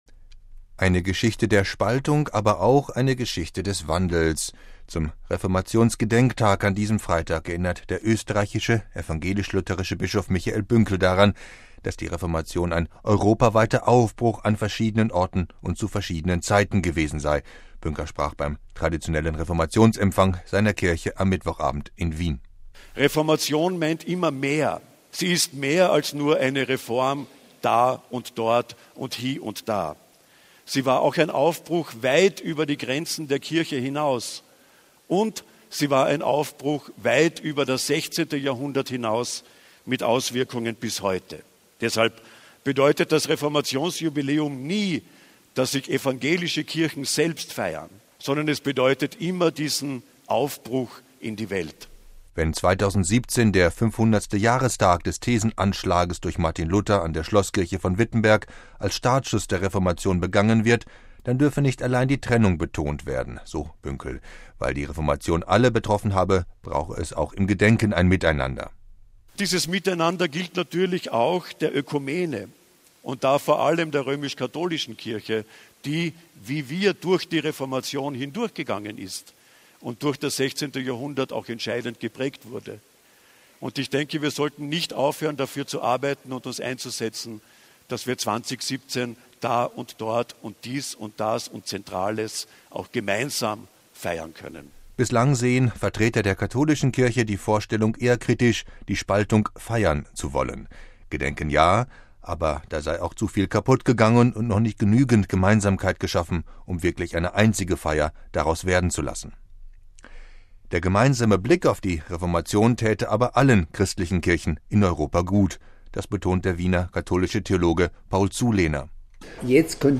Bünker sprach beim traditionellen Reformationsempfang seiner Kirche am Mittwochabend in Wien.
Gerade das zunehmend säkulare Europa brauche das öffentliche Wirken der Kirchen und Religionen, so der Bischof in seiner Rede in der Akademie der Wissenschaften.